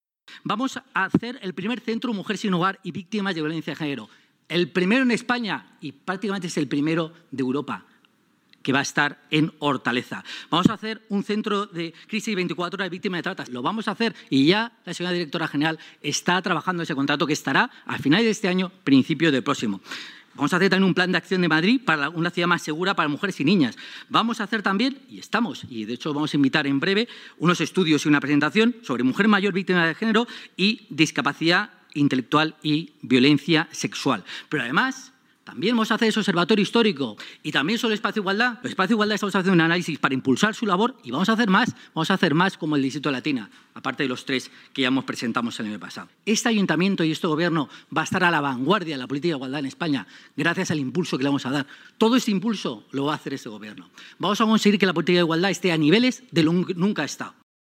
Durante la comisión del Área de Familias, Igualdad y Bienestar Social